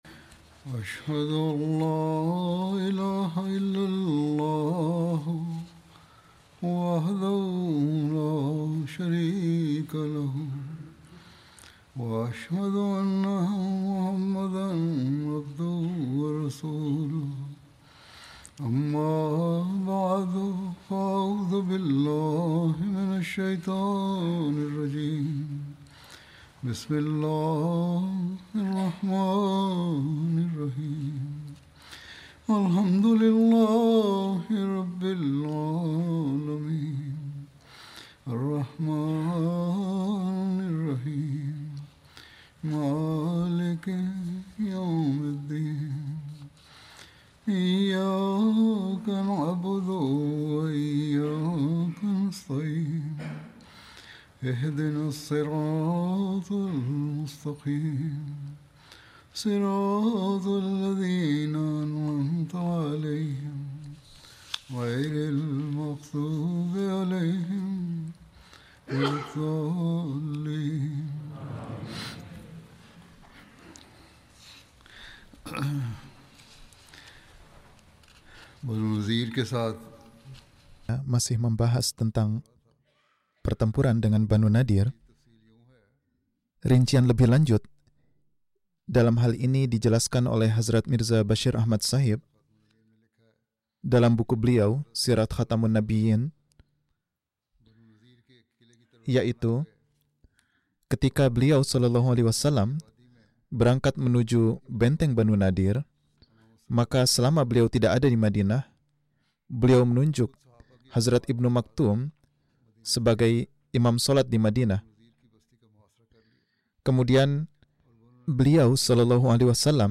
Indonesian translation of Friday Sermon